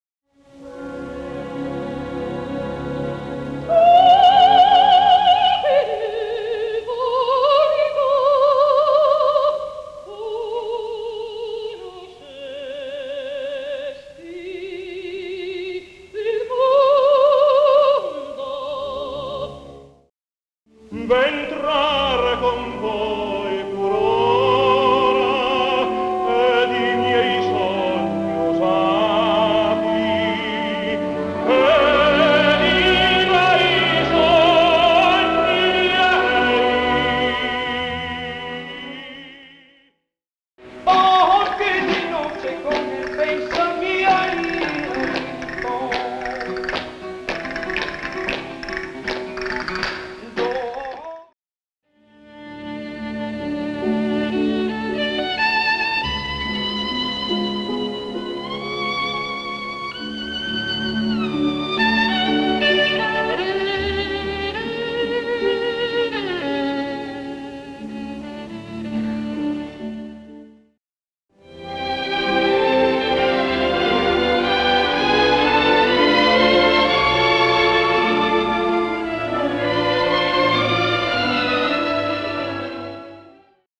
Complete Gala
(mono)
• Best sound available